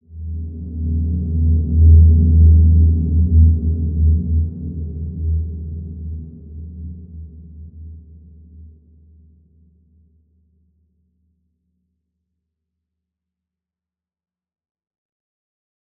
Large-Space-E2-mf.wav